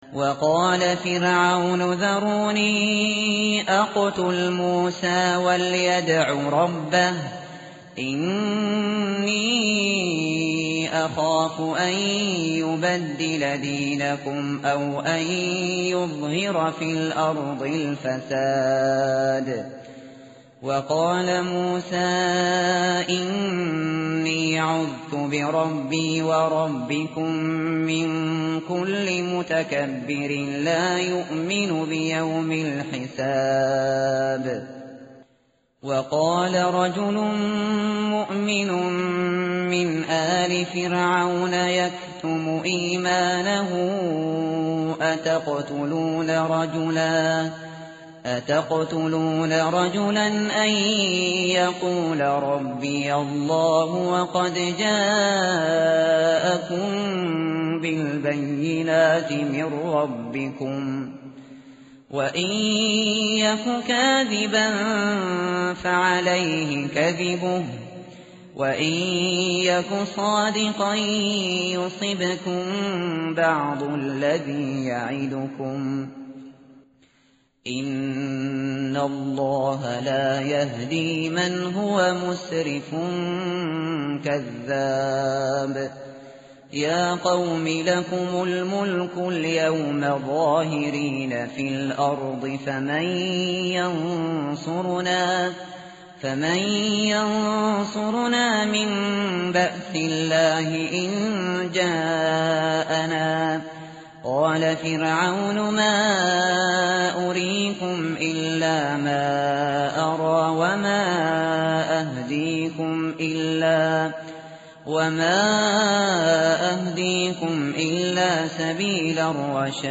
tartil_shateri_page_470.mp3